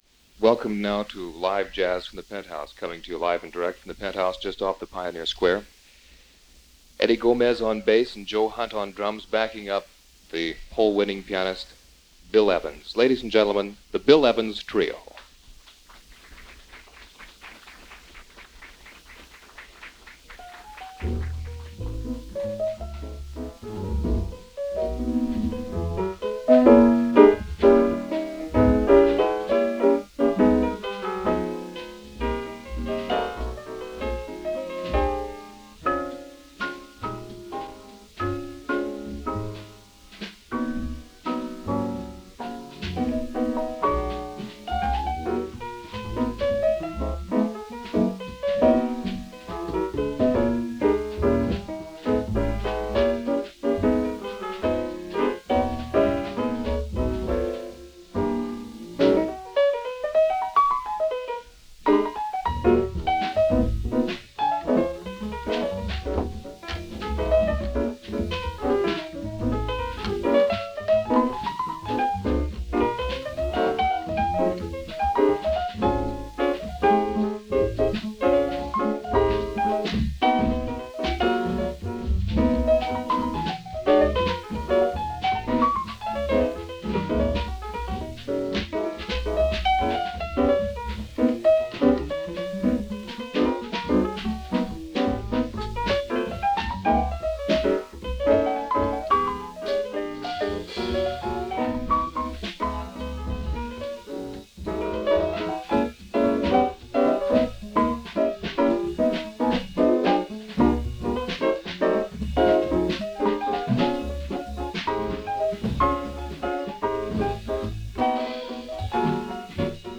live at The Penthouse, Seattle
bass
Drums
recorded live at The Penthouse in Seattle on May 12, 1966.